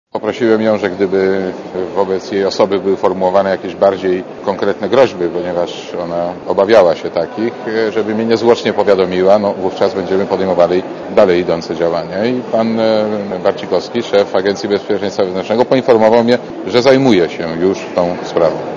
Mówi Marek Borowski